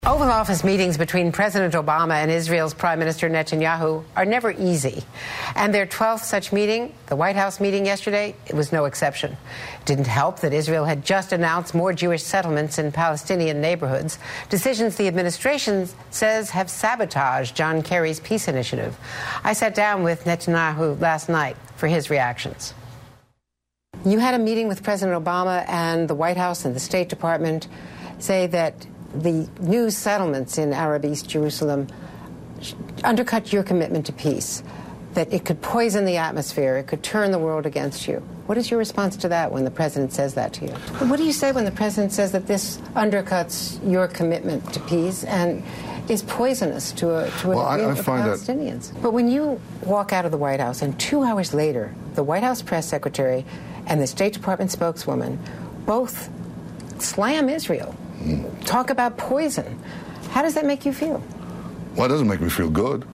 In a contentious interview with Benjamin Netanyahu aired on her 12 p.m. ET hour MSNBC show on Thursday, host Andrea Mitchell badgered the Israeli Prime Minister by repeatedly parroting a nasty attack line from the Obama administration: "...the White House and the State Department say that the new settlements in Arab East Jerusalem undercut your commitment to peace.